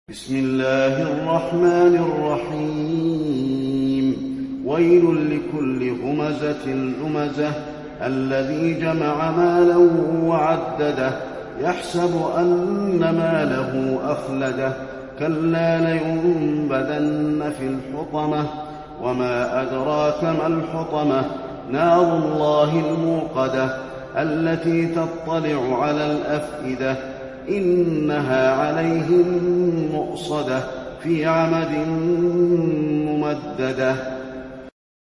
المكان: المسجد النبوي الهمزة